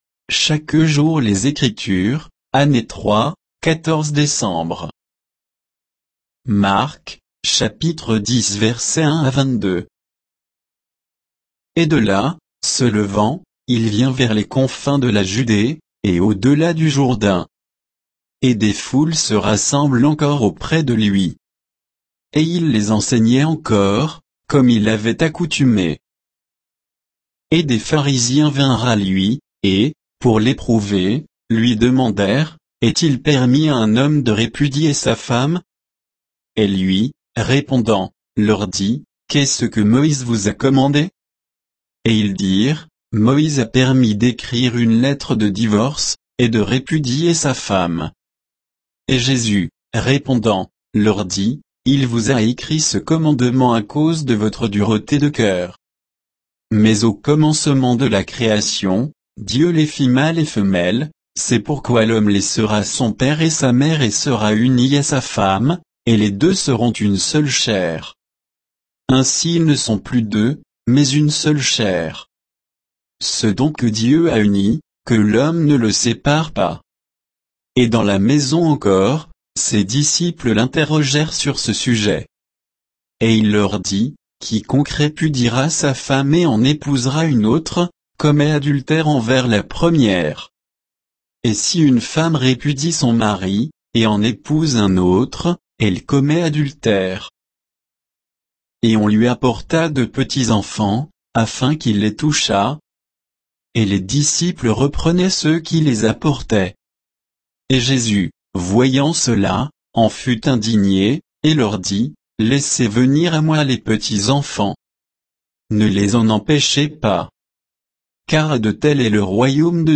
Méditation quoditienne de Chaque jour les Écritures sur Marc 10